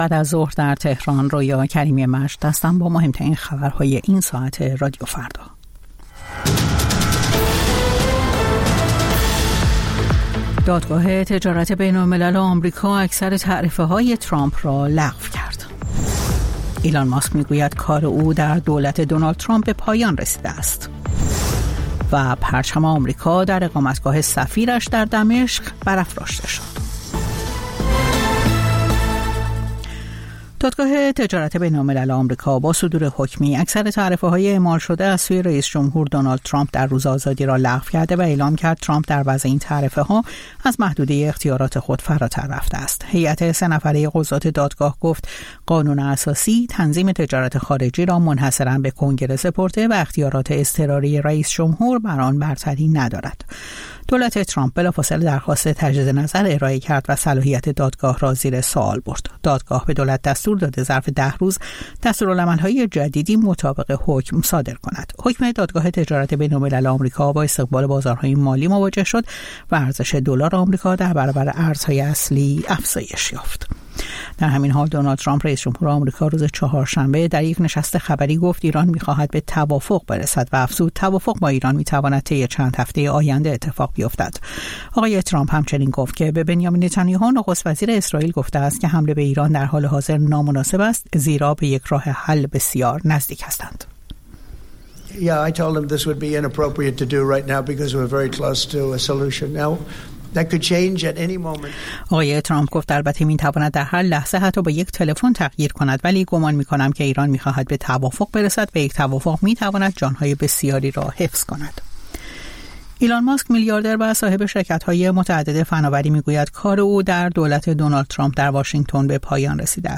سرخط خبرها ۱۵:۰۰